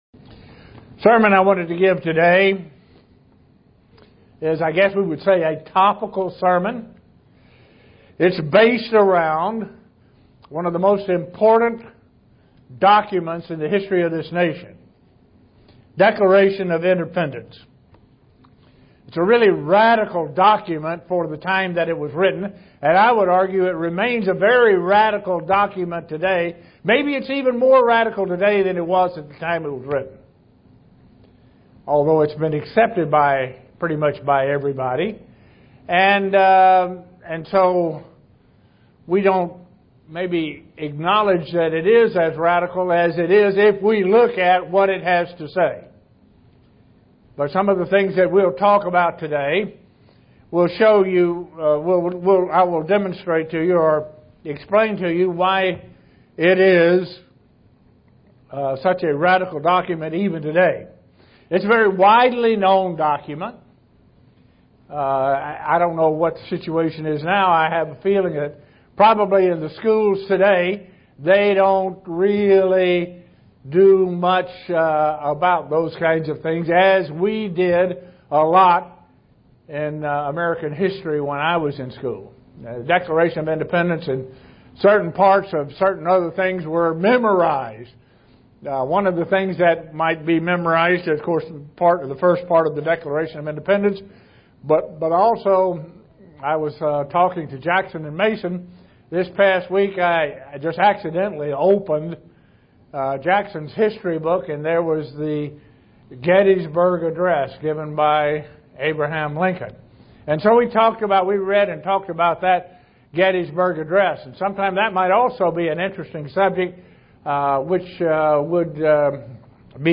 Print A Biblical view of the Declaration of Independence SEE VIDEO BELOW UCG Sermon Studying the bible?